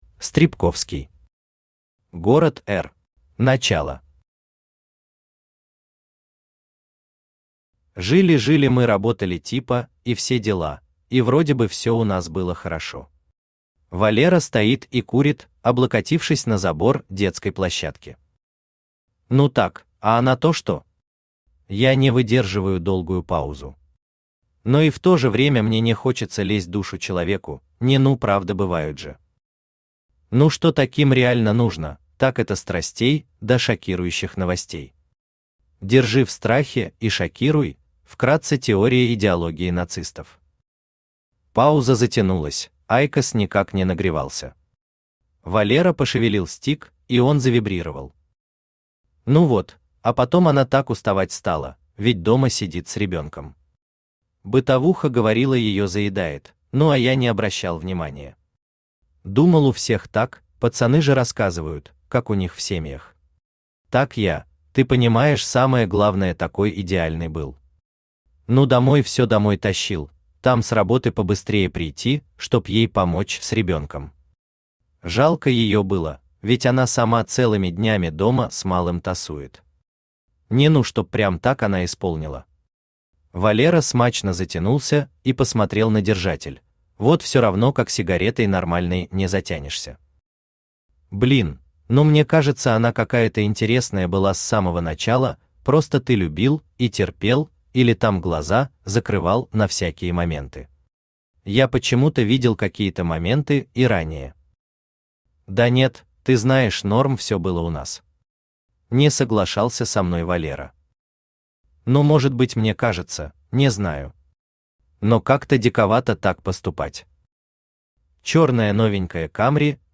Аудиокнига Город R | Библиотека аудиокниг
Aудиокнига Город R Автор Стребковский Читает аудиокнигу Авточтец ЛитРес.